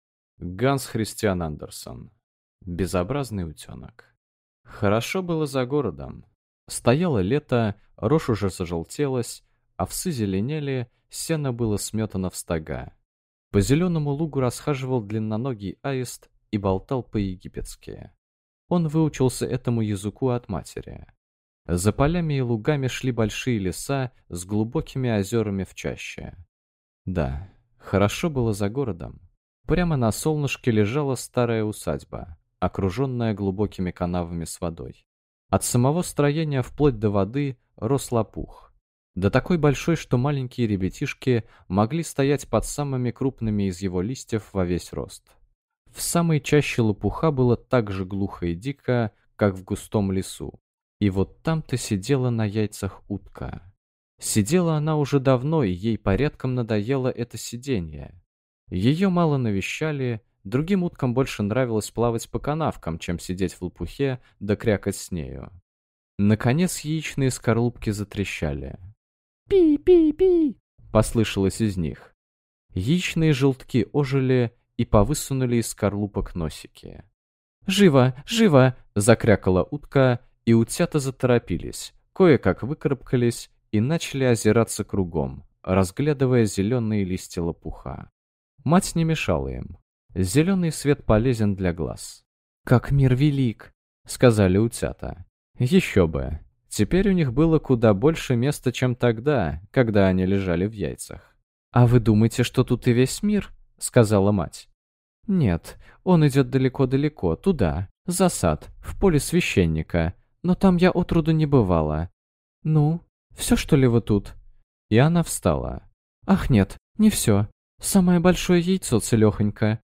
Аудиокнига Безобразный утёнок | Библиотека аудиокниг
Прослушать и бесплатно скачать фрагмент аудиокниги